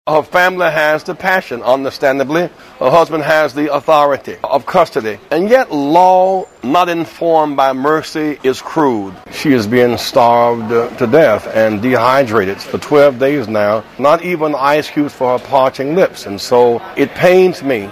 Mówi wielebny Jesse Jackson